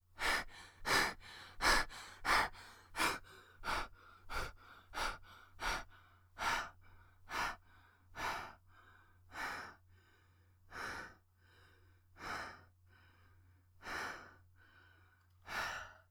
breath-female.wav